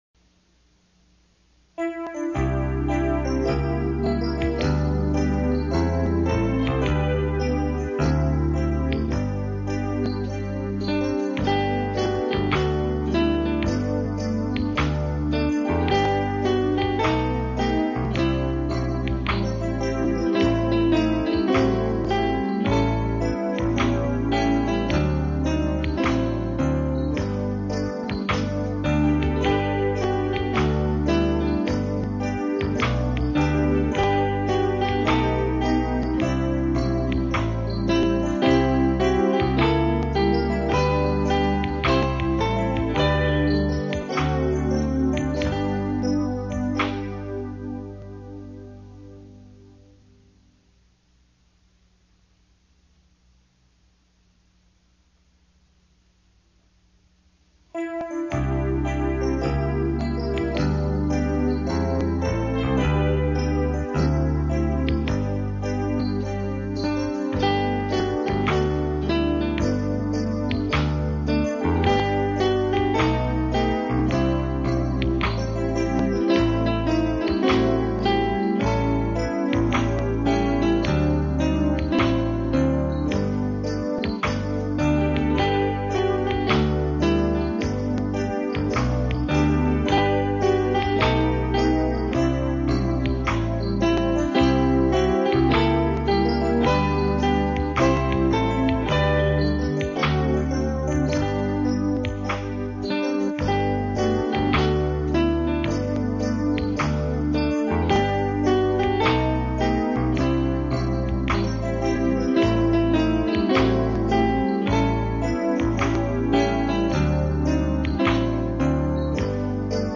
Description: This is the Pathfinder Song without any lyrics.
Pathfinder-SongNoWords.mp3